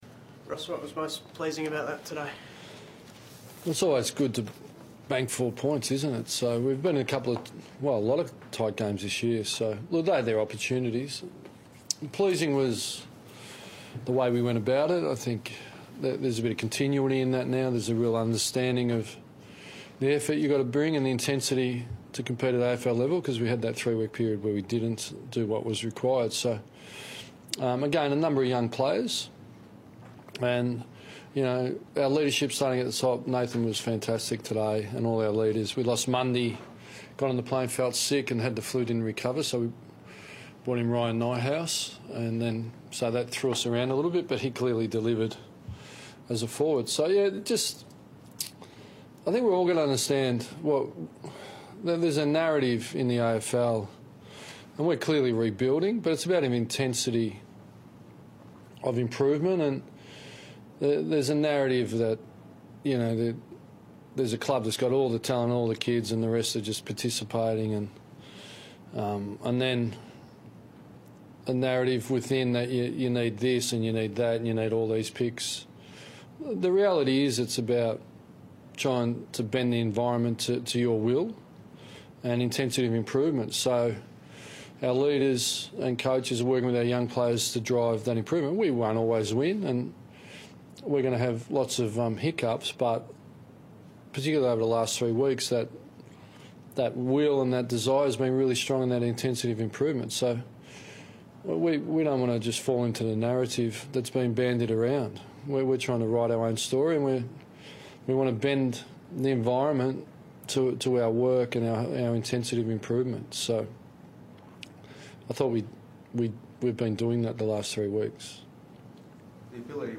Ross Lyon spoke to the media following the four-point win over North Melbourne